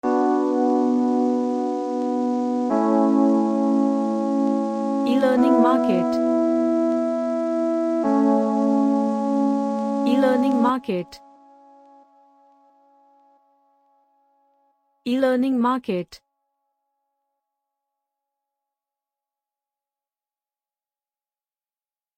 A Relaxing ambient track with vinyl melody.
Relaxation / Meditation